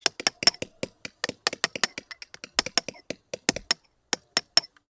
TOOL_Chisel_Sequence_06_mono.wav